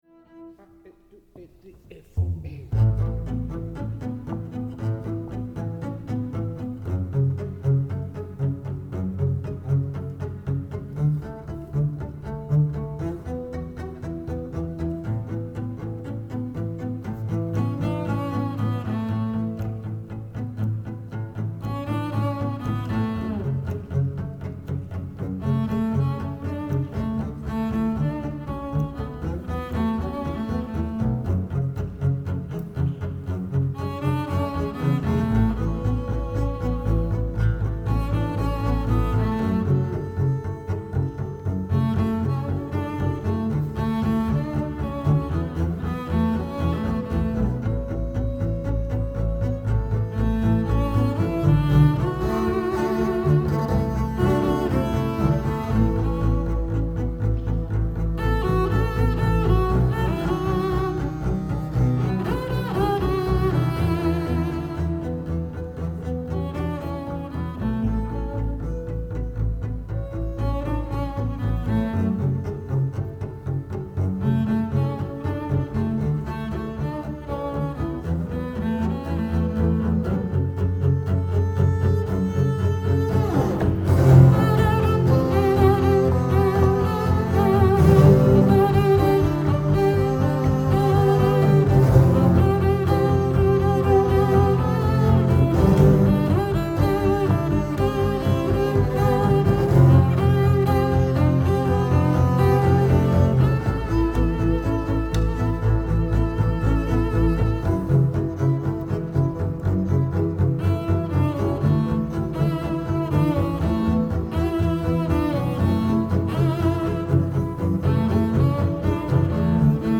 Live Recordings from the Concert at
WATANABE MEMORIAL HALL